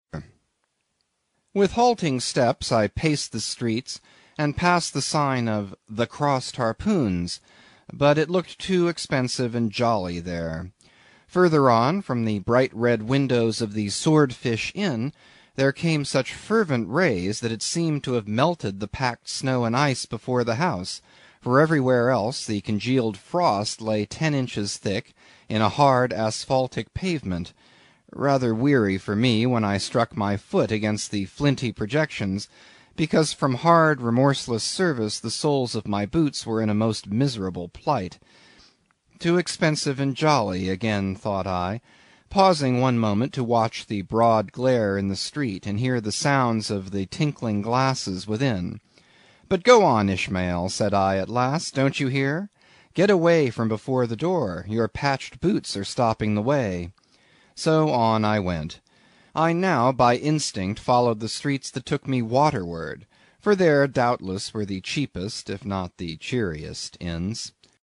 英语听书《白鲸记》第182期 听力文件下载—在线英语听力室